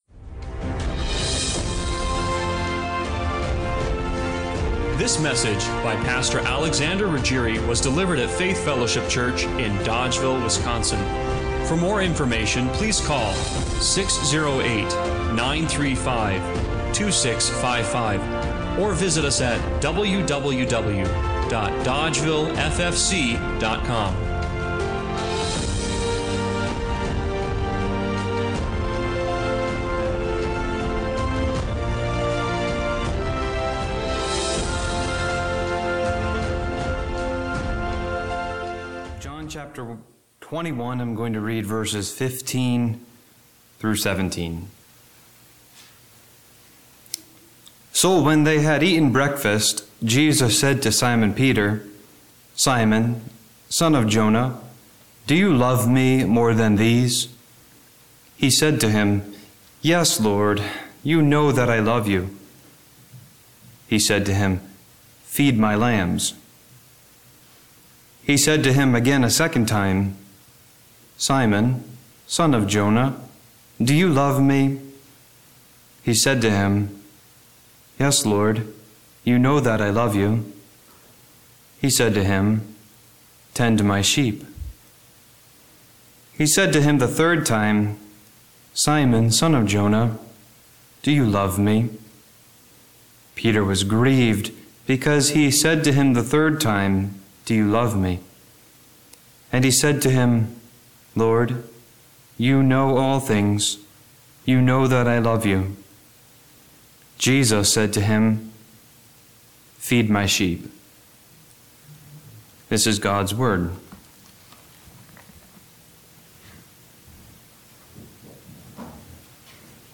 John 21:15-17 Service Type: Sunday Morning Worship Do you love Jesus